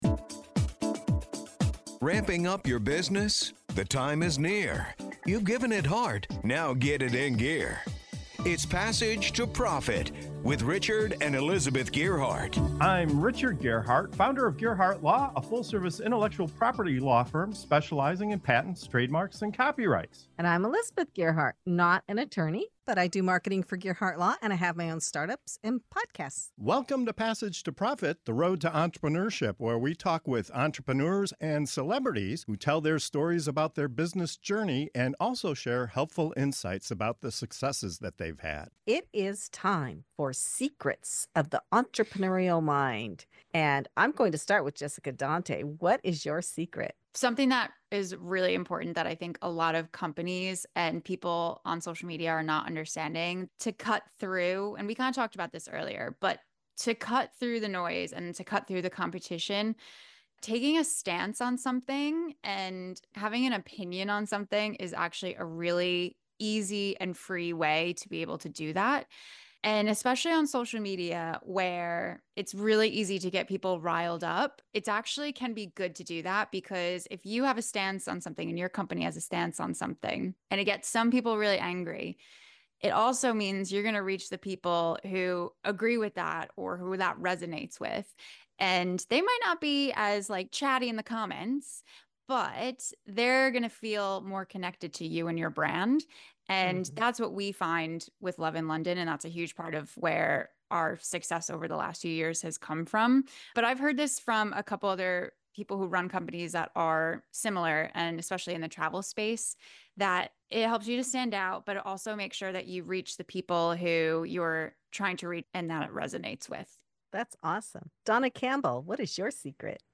In this segment of "Secrets of the Entrepreneurial Mind" on Passage to Profit Show, five dynamic leaders reveal the unexpected truths that fuel their success—from the power of taking a stand on social media to the emotional magic of storytelling, the art of receiving to give, and the underrated strength of simply reaching out and networking.